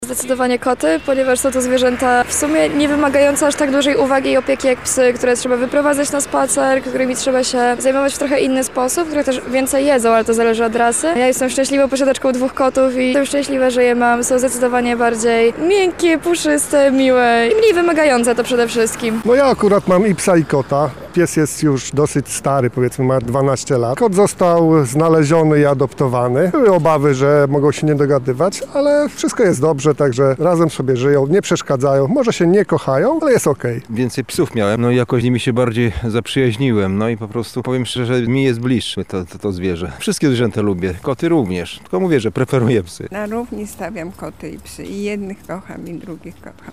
Postanowiliśmy z tej okazji zapytać mieszkańców naszego miasta, który z pupili cieszy się większą popularnością wśród domowników.
sonda